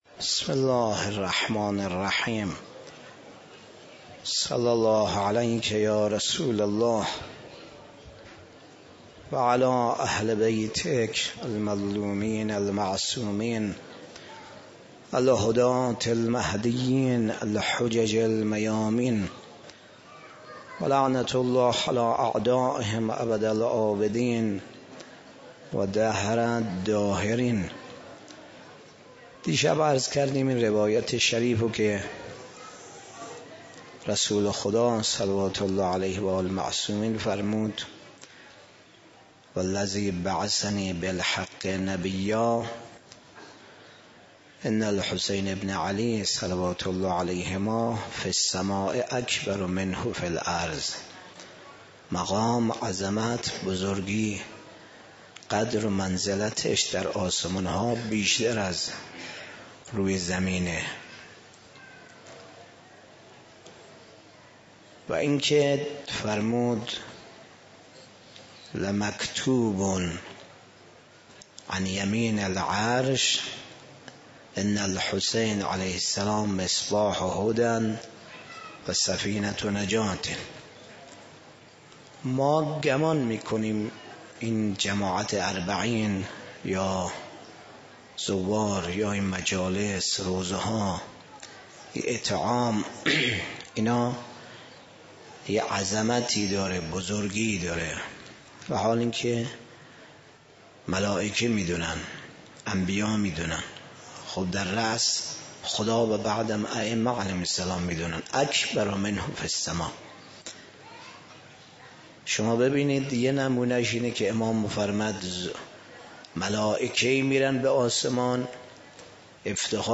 سيزدهم محرم 95 - مسجد امام منتظر علیه السلام -سخنرانی